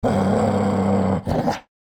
Minecraft Version Minecraft Version latest Latest Release | Latest Snapshot latest / assets / minecraft / sounds / mob / wolf / grumpy / growl3.ogg Compare With Compare With Latest Release | Latest Snapshot
growl3.ogg